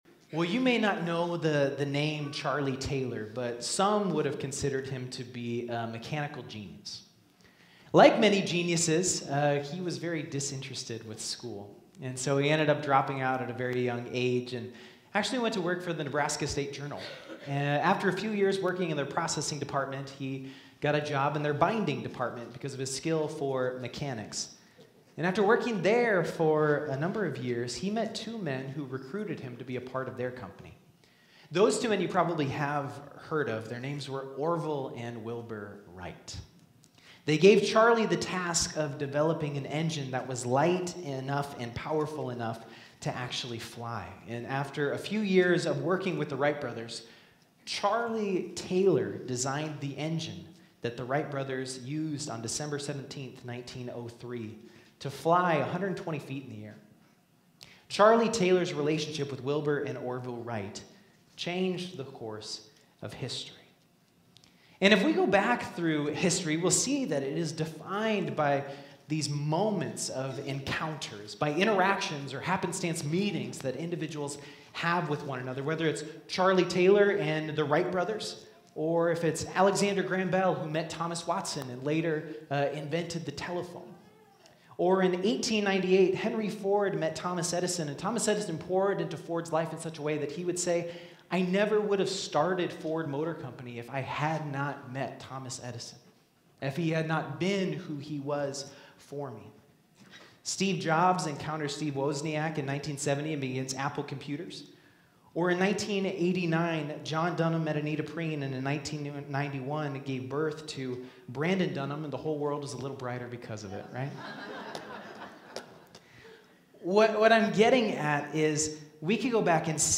Sermons | Harrisburg United Methodist Church
Thank you for joining us for Sunday Worship.